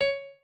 pianoadrib1_50.ogg